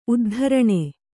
♪ uddharaṇe